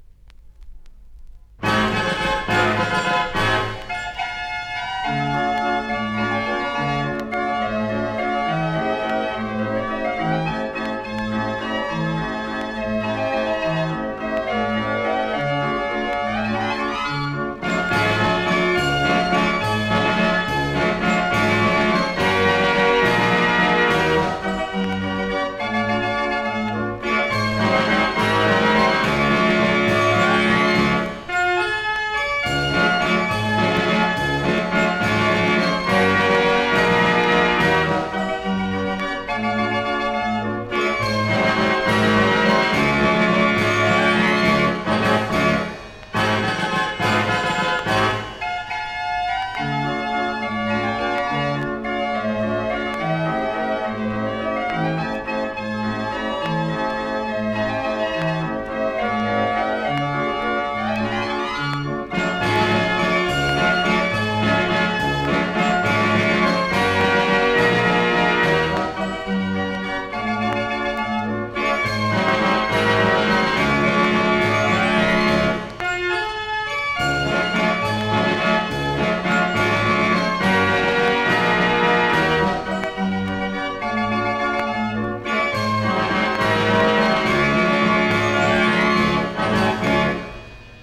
een beroemd Duits productiegebied voor draaiorgels.
deze orgels staan bekend om hun krachtige en precieze klank.